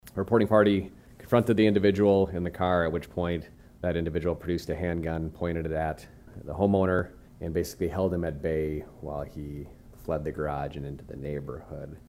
Police Chief Jon Thum.